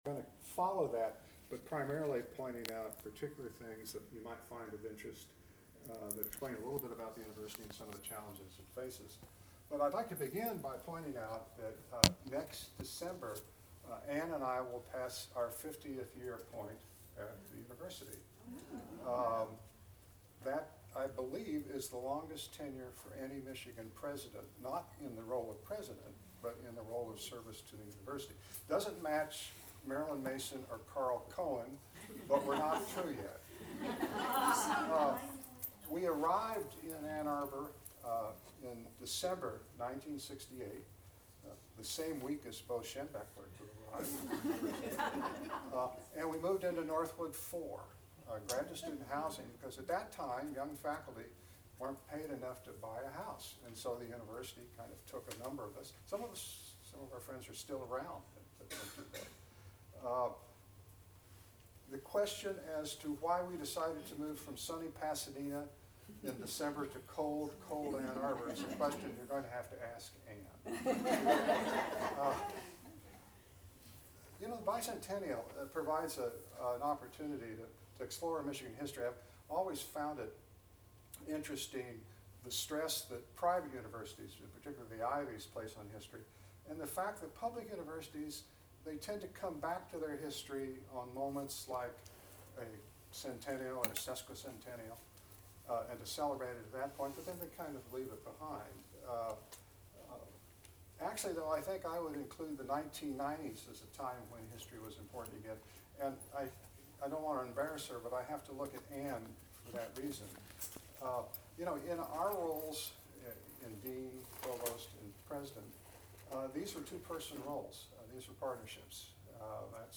Speaker: James Duderstadt, UM President Emeritus and Professor of Science and Engineering Recorded: September, 2017 as part of the FWC Living Room Chat series Length: 59 minutes